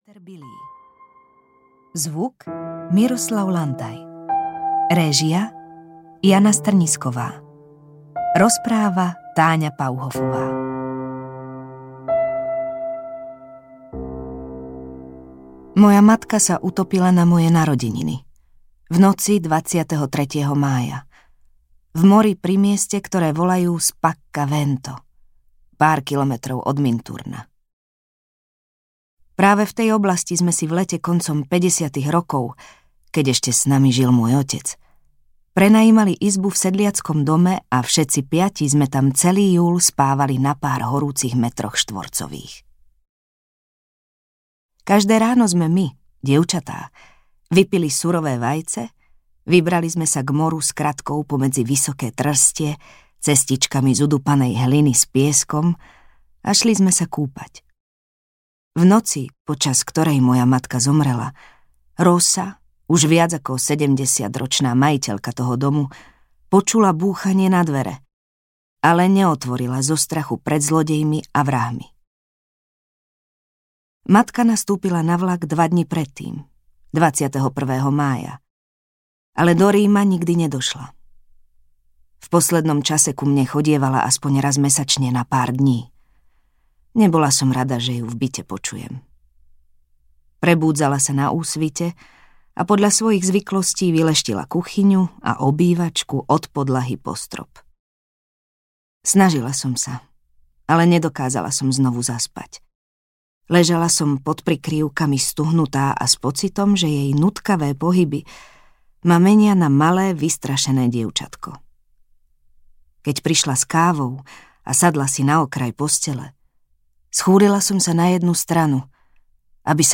Zraňujúca láska audiokniha
Ukázka z knihy
• InterpretTáňa Pauhofová